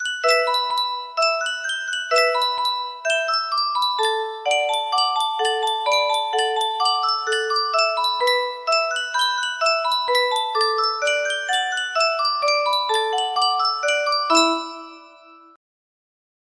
Yunsheng Music Box - Cooley's Y822 music box melody
Full range 60